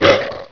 1 channel
pain2.wav